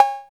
50 808 BELL.wav